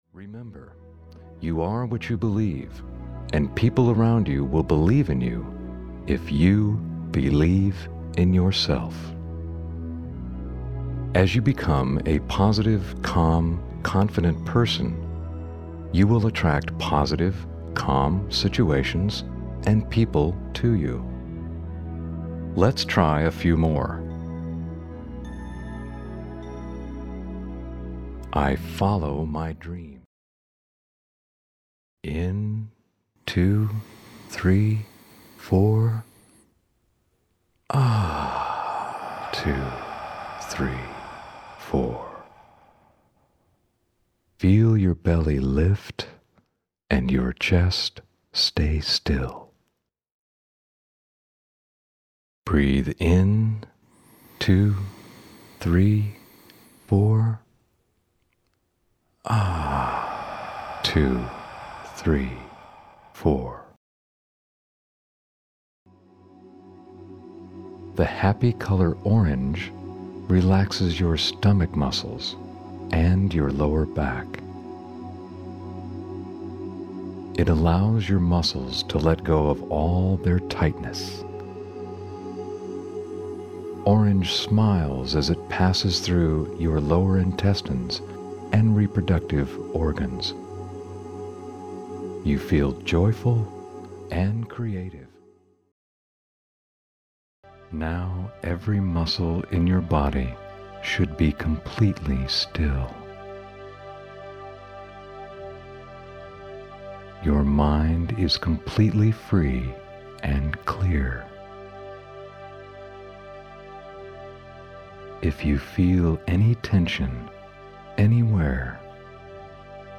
The techniques are accompanied by soothing, uplifting music to further enhance your relaxation experience.
Male narration is accompanied by calming music.